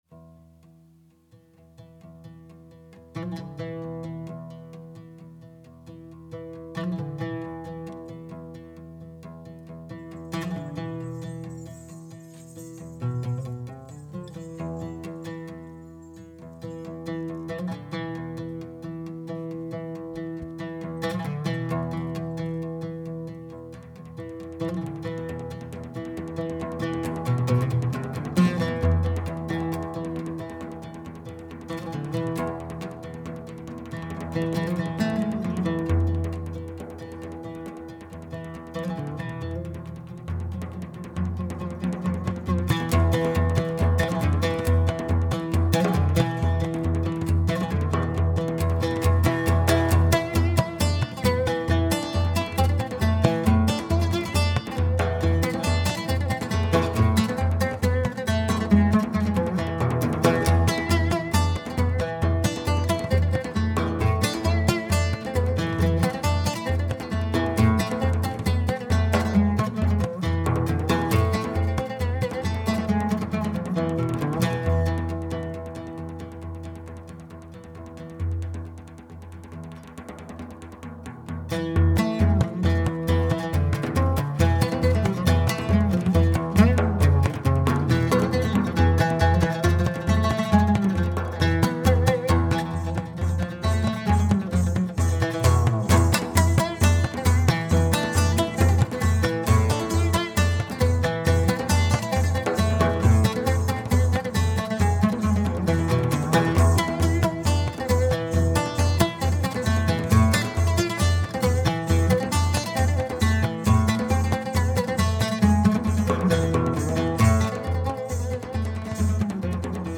Live at College of the Atlantic 9/5/07
Turkish folk tune
Our rendition is a bit snappier than the original